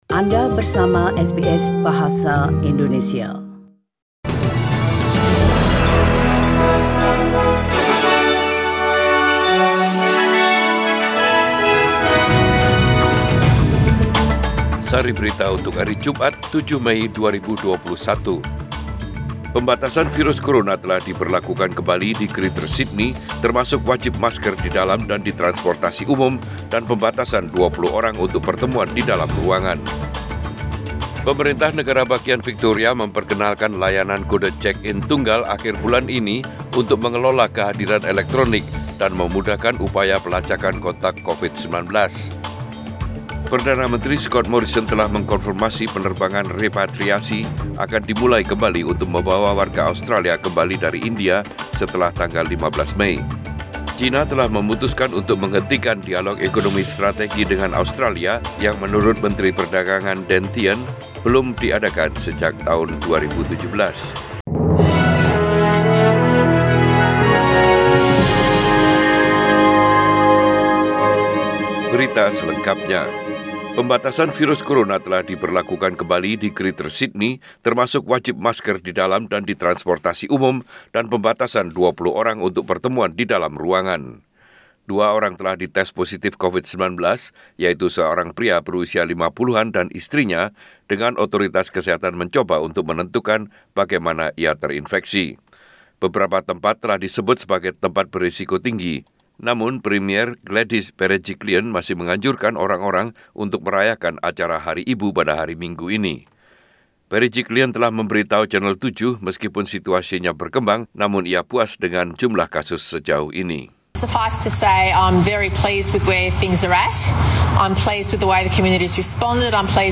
SBS Radio News in Bahasa Indonesia - 7 May 2021